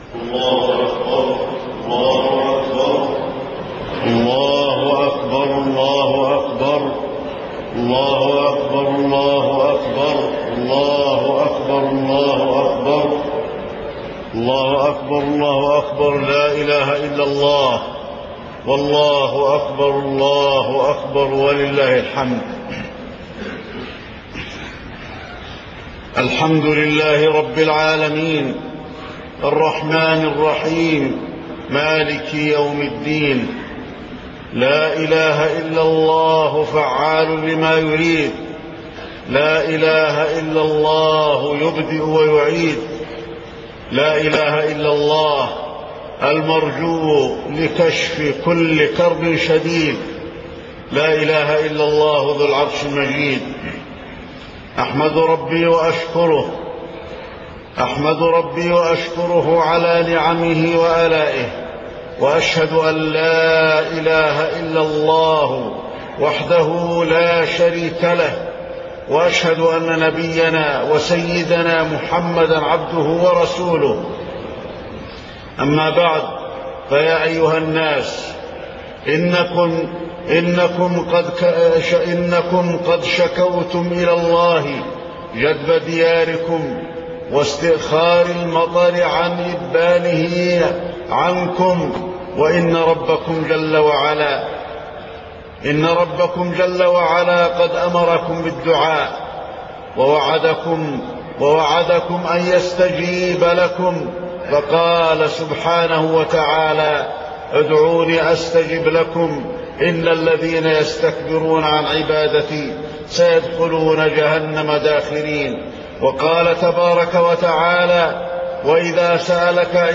خطبة الاستسقاء - المدينة- الشيخ علي الحذيفي - الموقع الرسمي لرئاسة الشؤون الدينية بالمسجد النبوي والمسجد الحرام
تاريخ النشر ٢٨ ذو القعدة ١٤٢٩ هـ المكان: المسجد النبوي الشيخ: فضيلة الشيخ د. علي بن عبدالرحمن الحذيفي فضيلة الشيخ د. علي بن عبدالرحمن الحذيفي خطبة الاستسقاء - المدينة- الشيخ علي الحذيفي